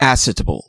Ääntäminen
Ääntäminen US US Tuntematon aksentti: IPA : /ˈæ.sɪ.tə.bəl/ Haettu sana löytyi näillä lähdekielillä: englanti Käännöksiä ei löytynyt valitulle kohdekielelle. Määritelmät Substantiivi An ancient Roman measure, equivalent to about one eighth of a pint .